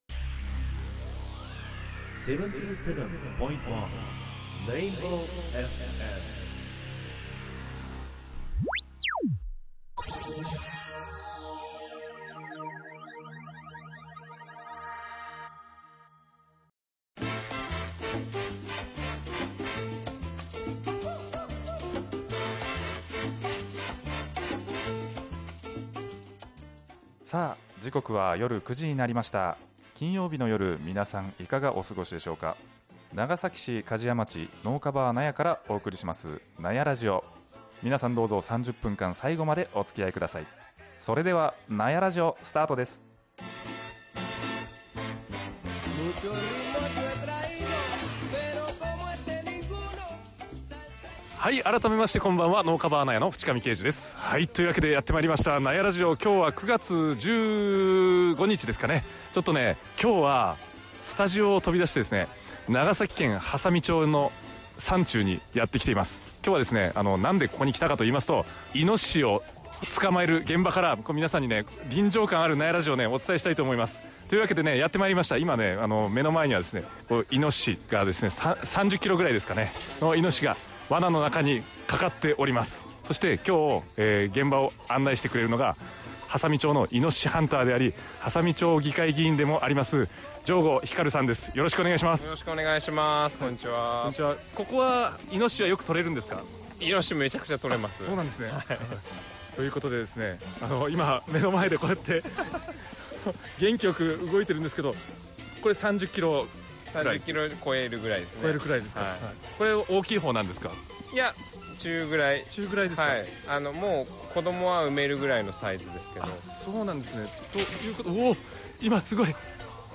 今回は初めてスタジオを飛び出して、現場からお伝えします。
今回私は、イノシシが捕獲されているところから、お肉になるところまでのお仕事に同行して、全部見せてもらいました。同行させていただいたのは、ハンターで波佐見町議会議員の城後光さん。